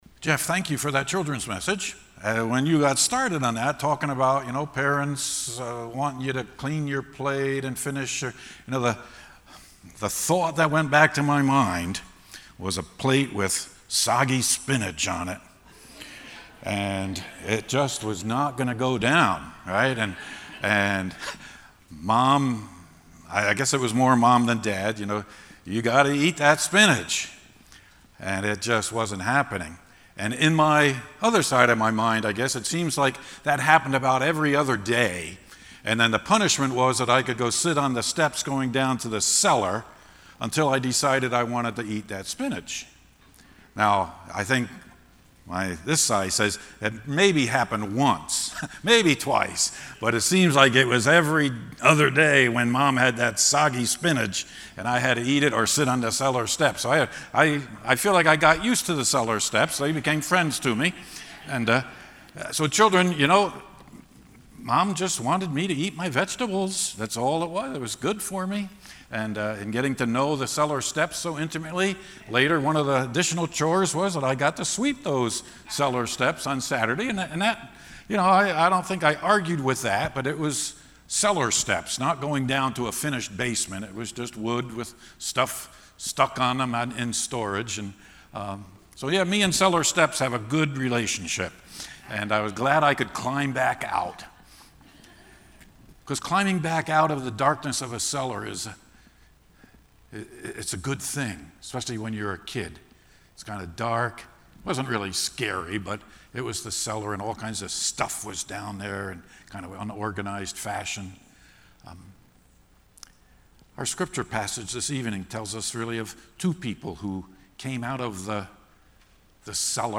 The messages from the morning and evening services at Second Christian Reformed Church of Kalamazoo, MI.